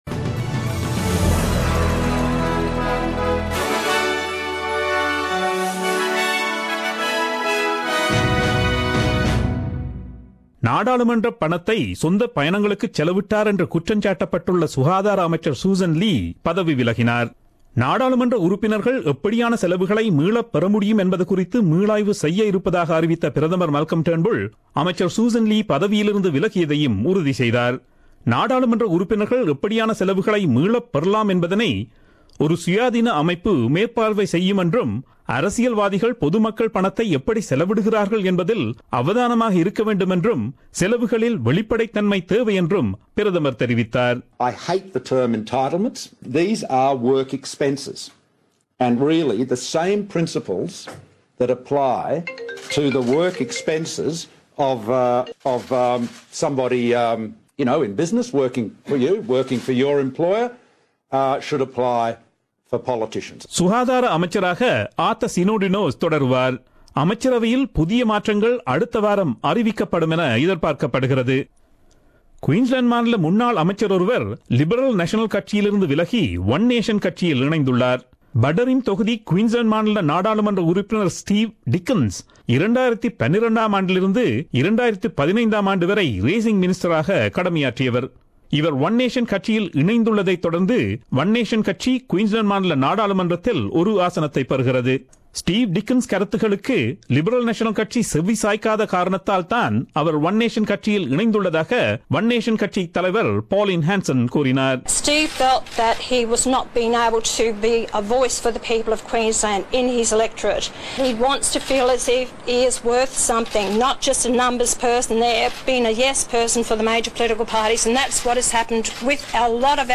Australian news bulletin aired on Friday 13 Jan 2017 at 8pm.